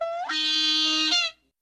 oooweeaaa.mp3